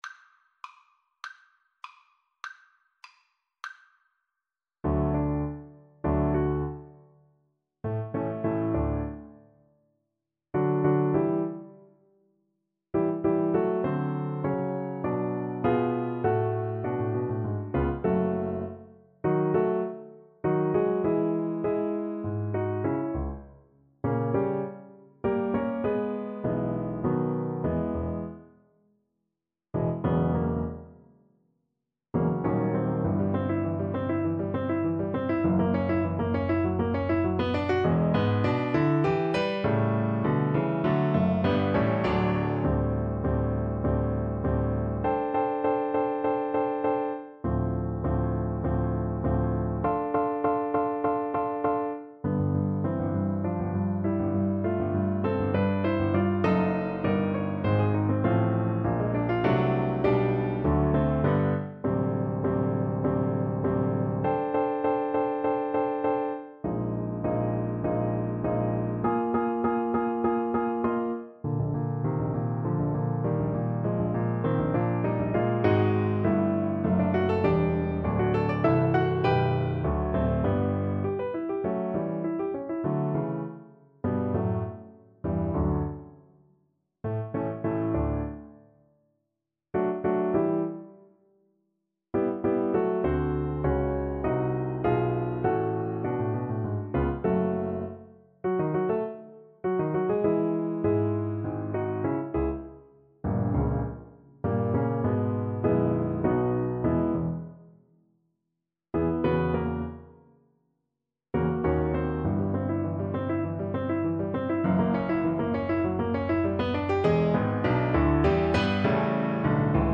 2/2 (View more 2/2 Music)
~ = 200 Allegro Animato (View more music marked Allegro)
Classical (View more Classical Cello Music)